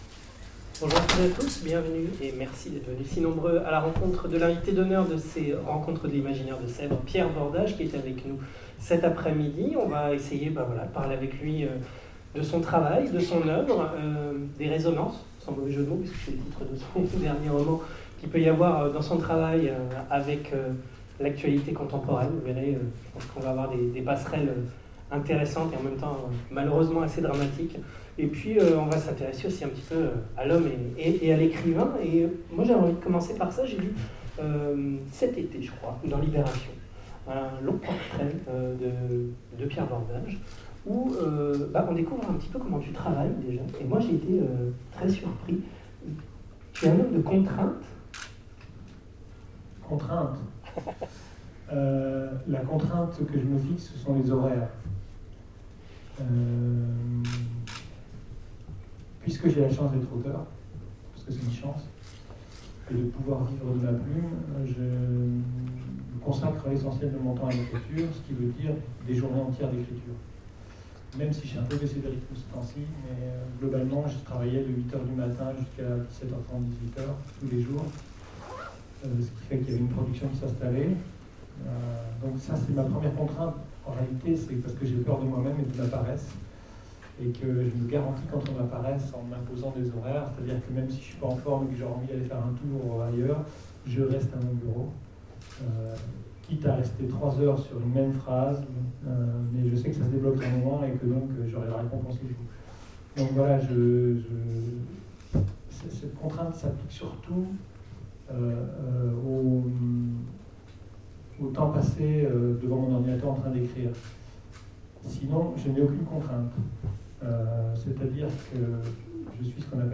- le 31/10/2017 Partager Commenter Sèvres 2015 : Rencontre avec Pierre Bordage Télécharger le MP3 à lire aussi Pierre Bordage Genres / Mots-clés Rencontre avec un auteur Conférence Partager cet article